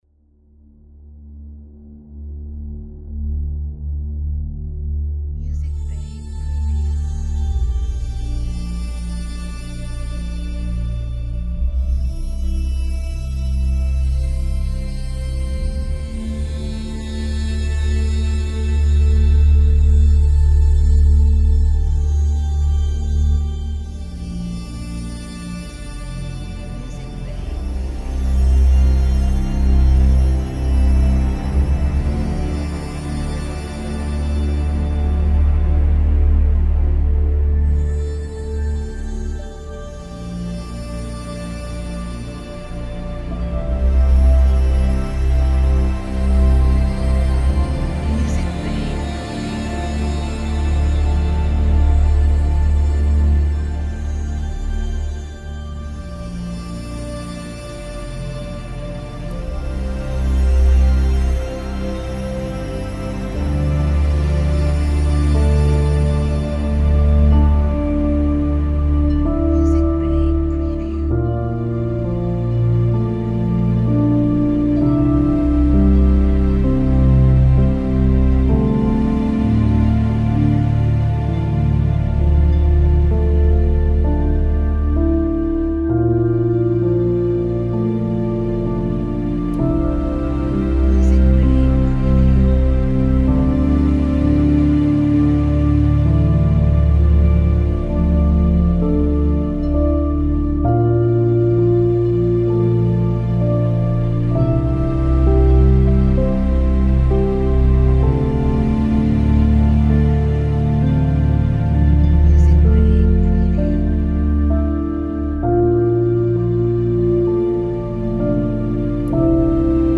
Cinematic music for filmmakers.